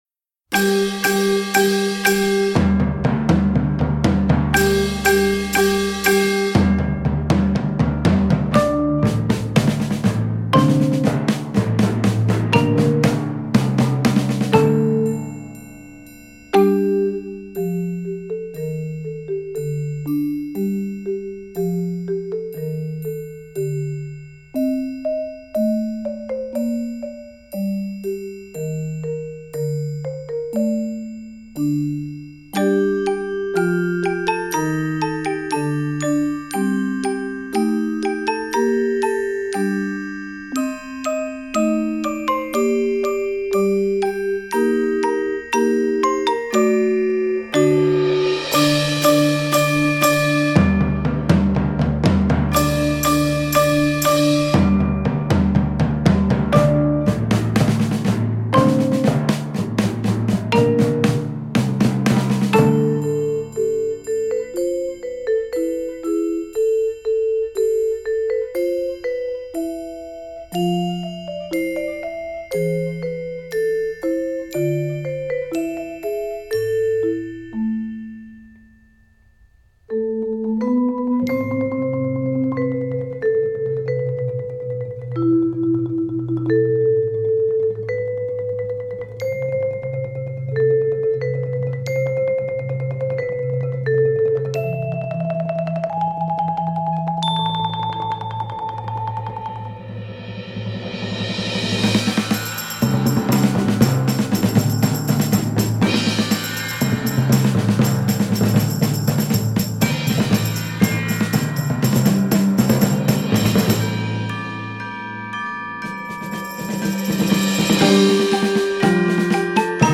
Voicing: 11 Percussion